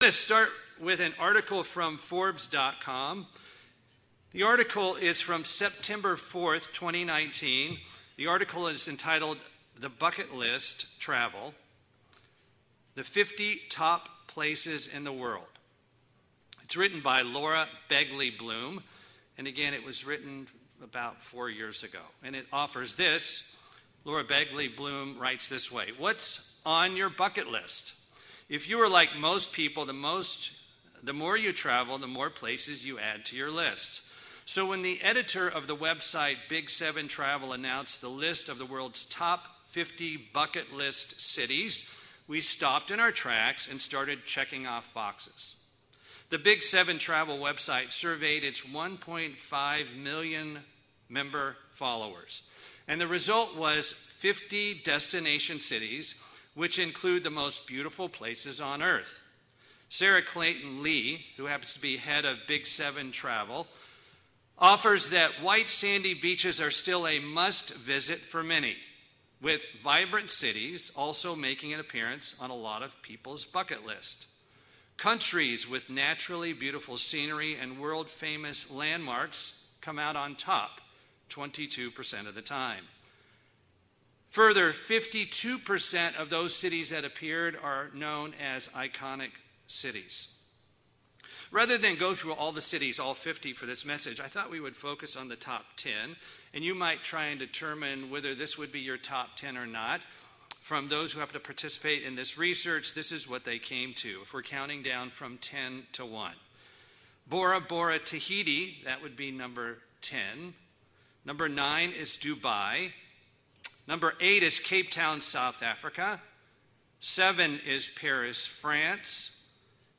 However, there is coming a new Jerusalem which should be high on our list of future visitation! [Note - We experienced some system problems during this message that affected the sound.]
Sermons